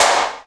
Index of /90_sSampleCDs/Roland L-CD701/KIT_Drum Kits 4/KIT_Hard Core
PRC DANZE 0H.wav